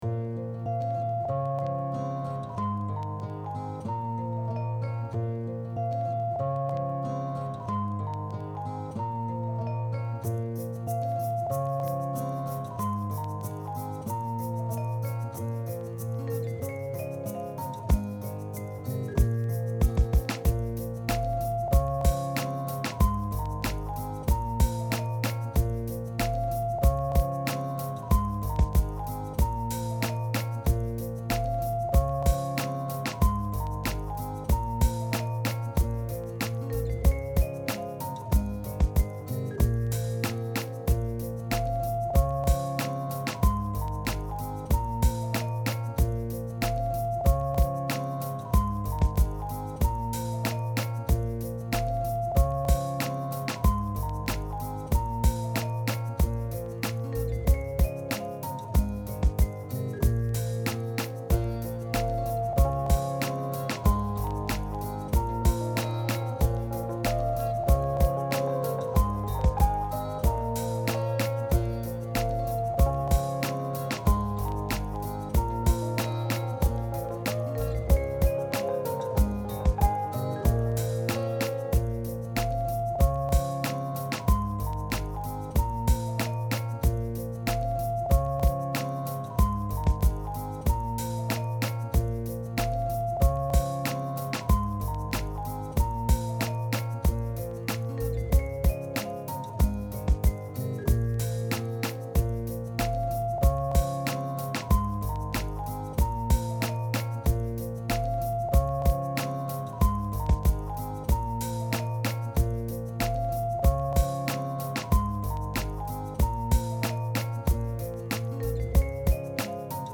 How does this mix sound (hip-hop beat)?